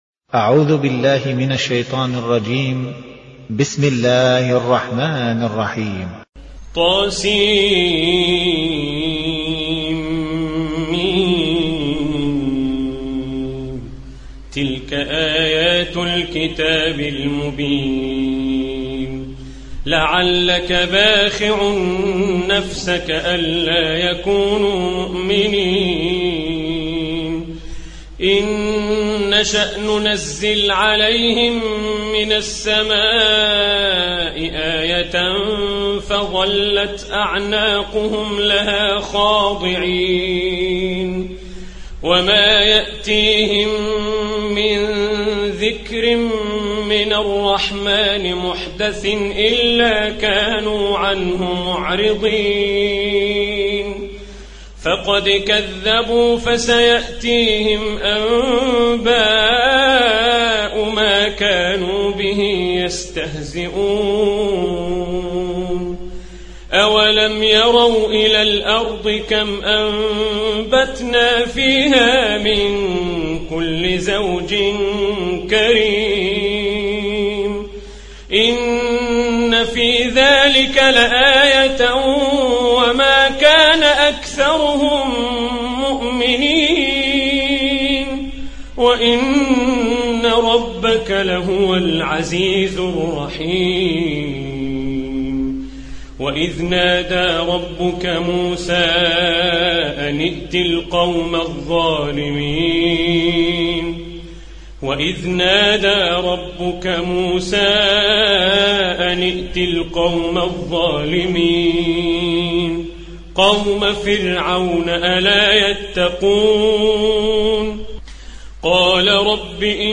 Hafs an Assim